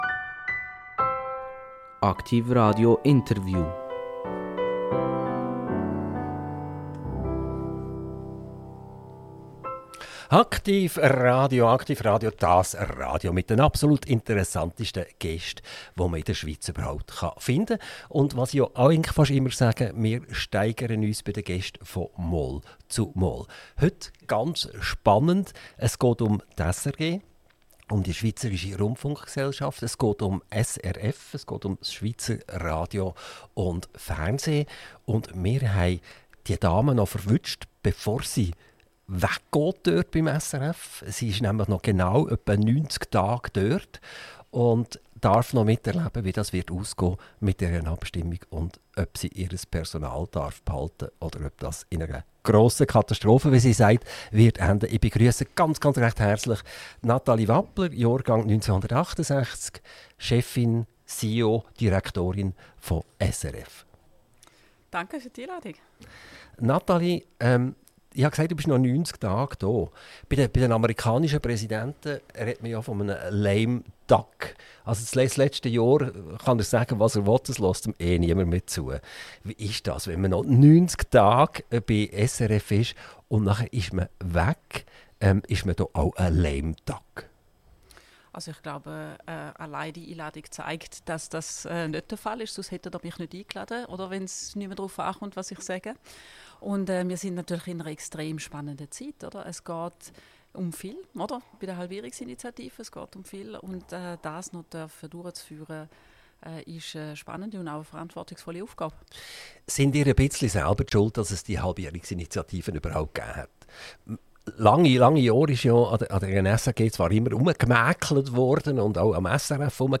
INTERVIEW - Nathalie Wappler Hagen - 30.01.2026 ~ AKTIV RADIO Podcast